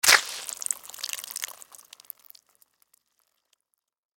Звуки вампиров
Вампир вонзается в шею, слышен хлюпающий звук крови